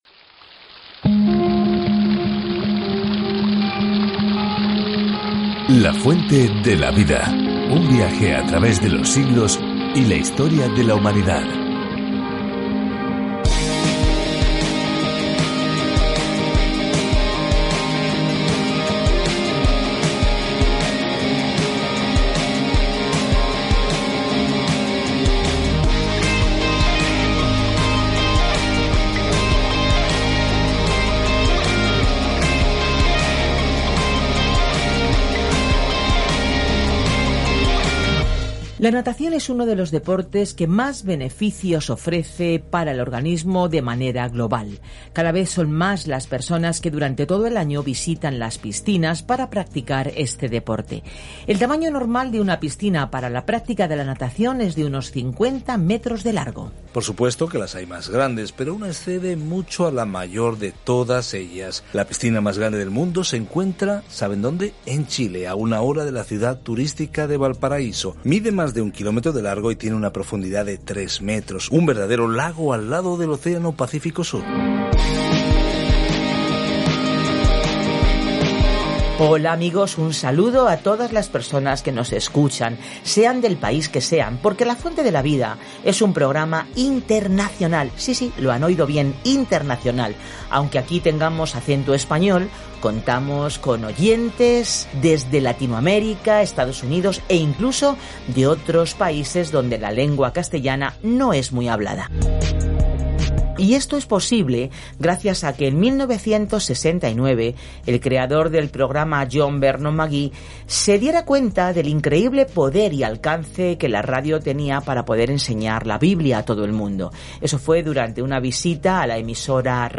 En adoración, sacrificio y reverencia, Levítico responde esa pregunta para el antiguo Israel. Viaja diariamente a través de Levítico mientras escuchas el estudio en audio y lees versículos seleccionados de la palabra de Dios.